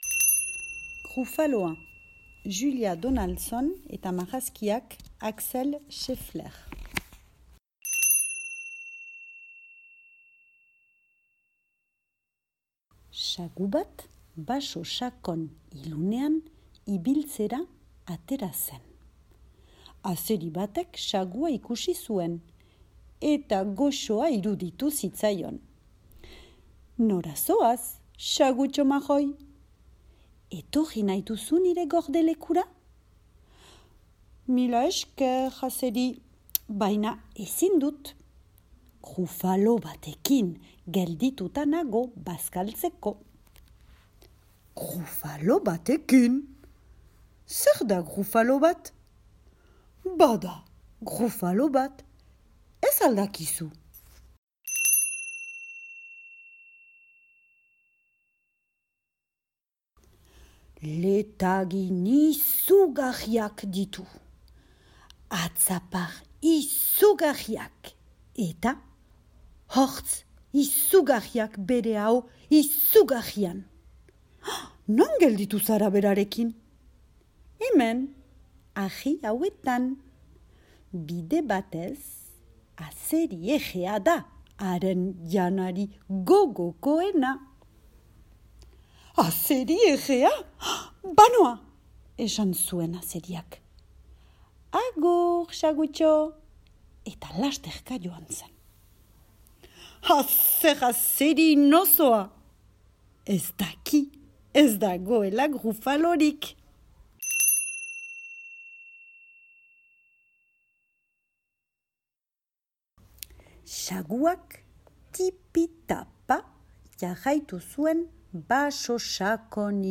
Grufaloa, ipuina entzungai